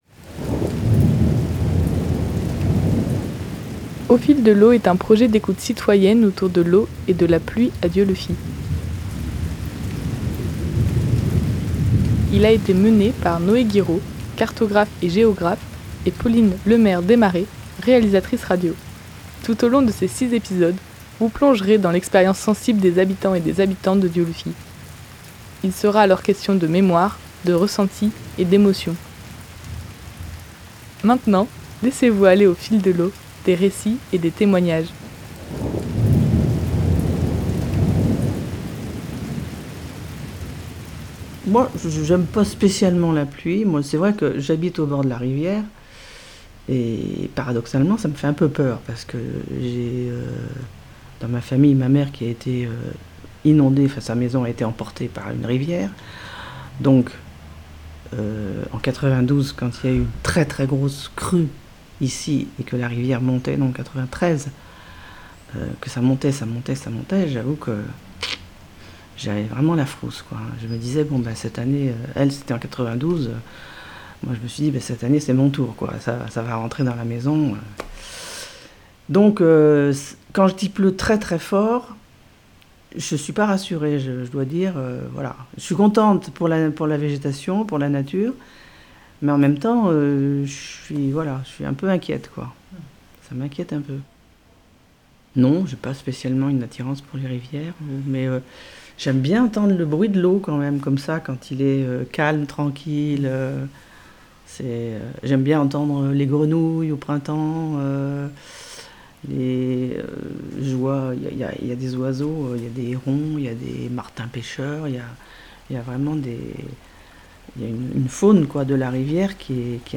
Ces épisodes sont ainsi un montage des récits d’expériences sensibles des habitants et habitantes de Dieulefit. Il est dès lors question de mémoire, de ressentit et d’émotions.